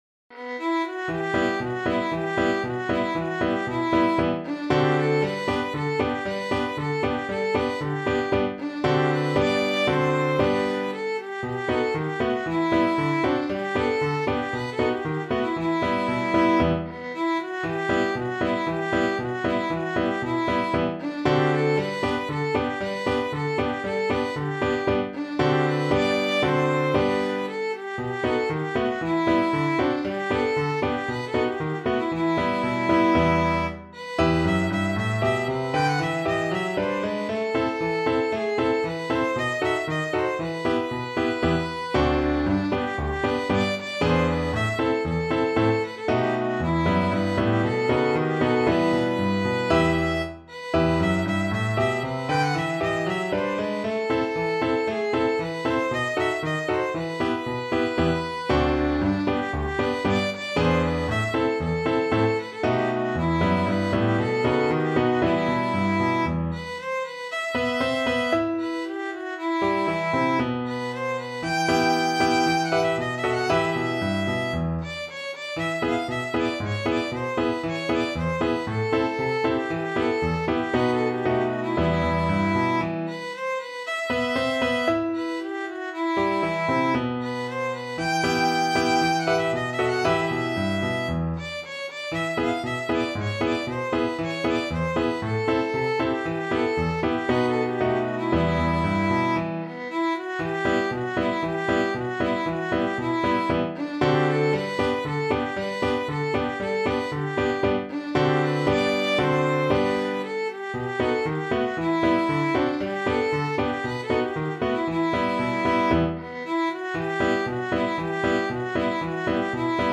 2/4 (View more 2/4 Music)
Allegro =c.116 (View more music marked Allegro)
world (View more world Violin Music)